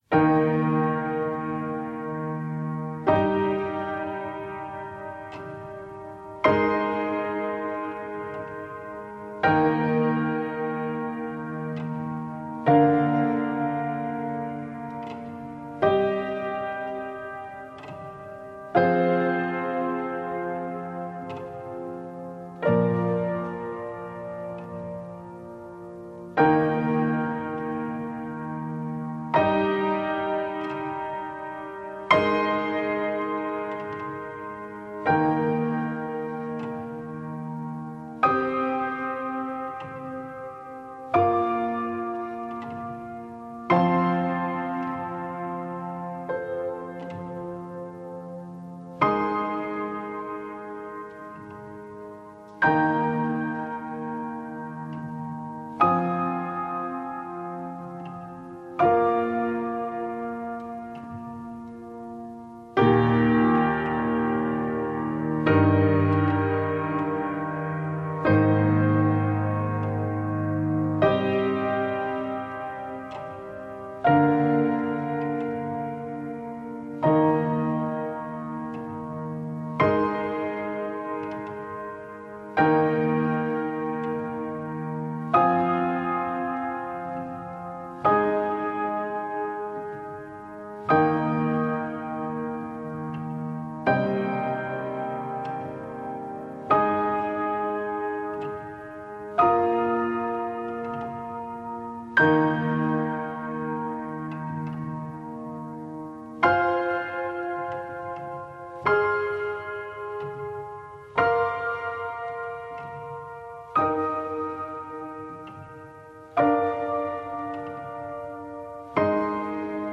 描述：电影|平静
Tag: 钢琴